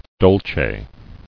[dol·ce]